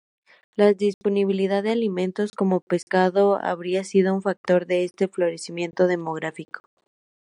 Read more factor Frequency C1 Hyphenated as fac‧tor Pronounced as (IPA) /faɡˈtoɾ/ Etymology Borrowed from Latin factor In summary Borrowed from Latin factor.